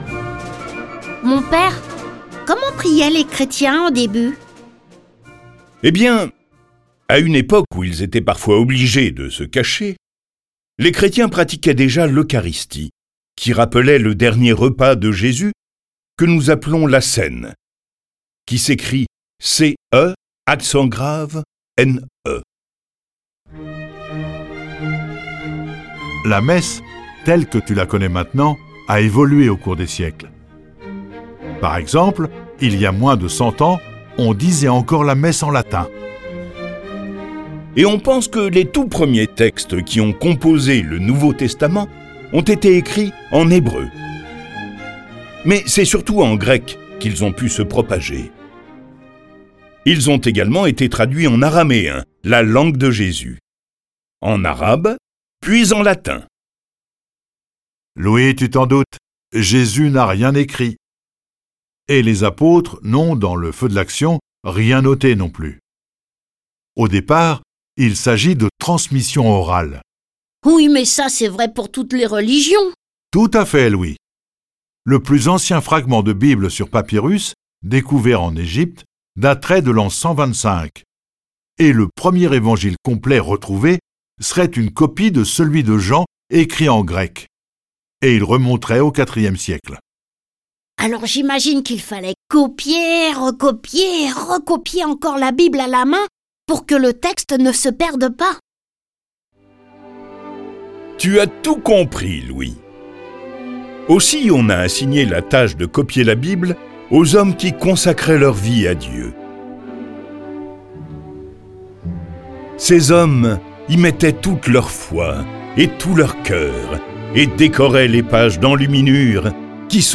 Diffusion distribution ebook et livre audio - Catalogue livres numériques
Ce récit est animé par 5 voix et accompagné de plus de 30 morceaux de musique classique.